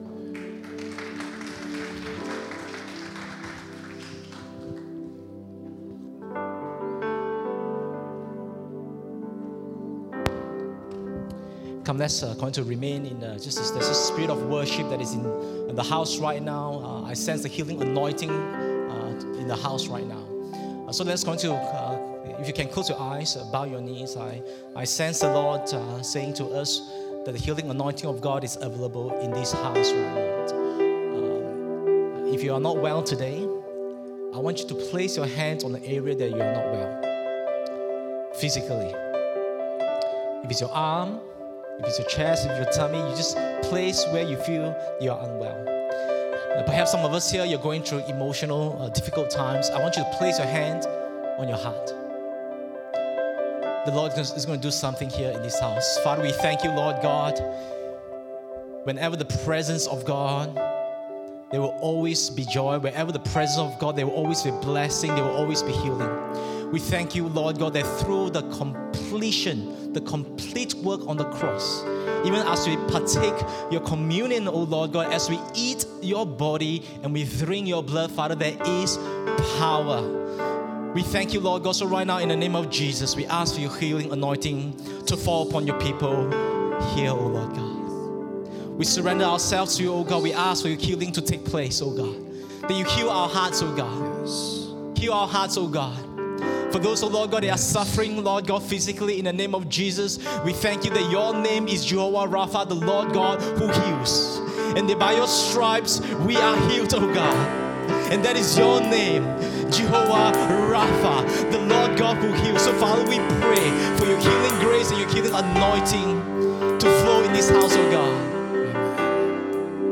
English Worship Service - 5th February 2023
Sermon Notes